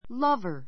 lover A2 lʌ́və r ら ヴァ 名詞 ❶ （～を） 愛する人, 愛好者[家] a lover of music=a music lover a lover of music=a music lover 音楽愛好者 ❷ （女性から見た男性の） 恋人 こいびと ; lovers で 恋人同士 Romeo was Juliet's lover.